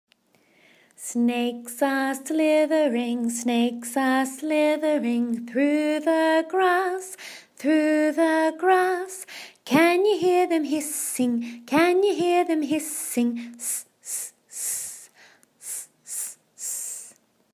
S Sound - Snake